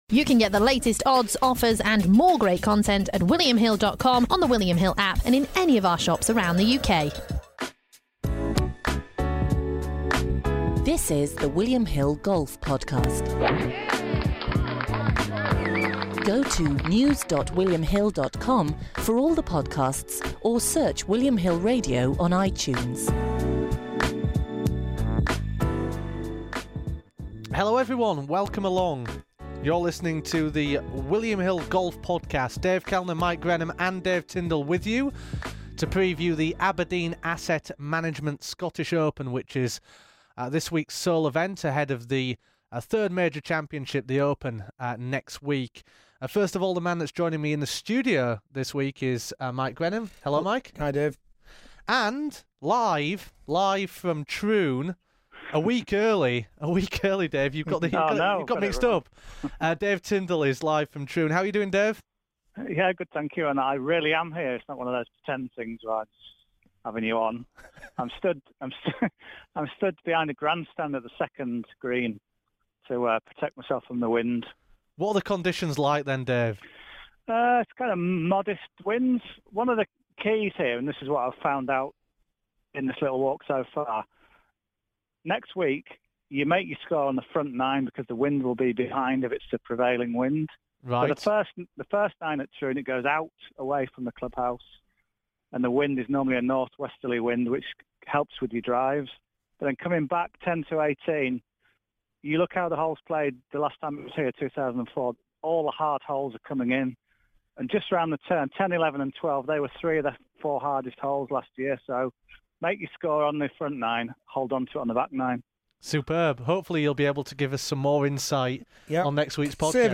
live from Troon!